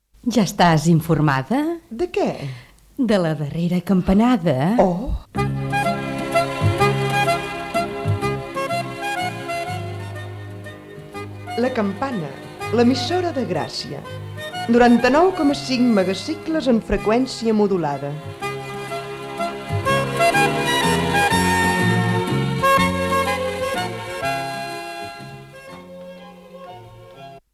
Indicatiu de la darrera campanada.